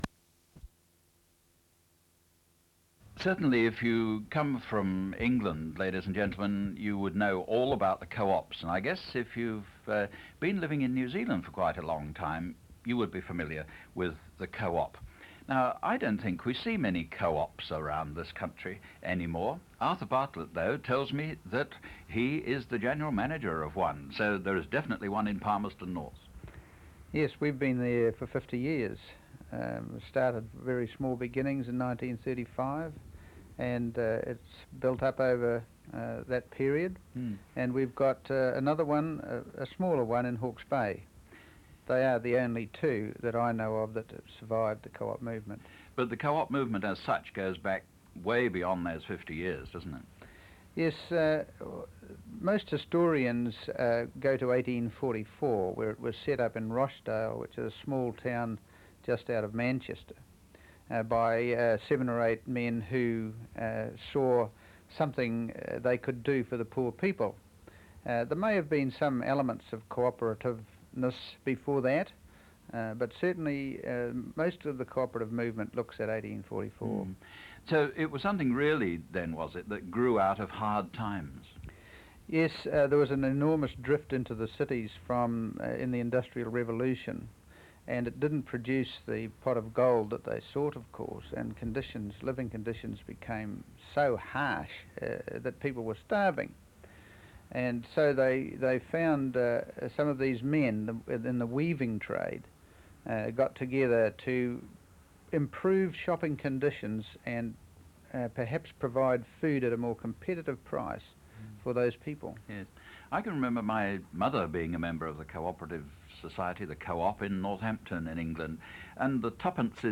Oral Interview - Consumers' Co-operative Society (Manawatu) Ltd - Manawatū Heritage